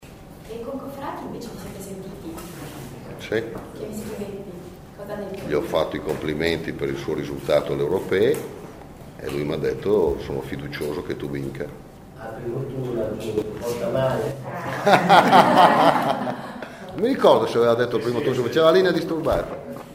Ha detto così questa mattina in una breve conferenza stampa convocata nella sede del Pd.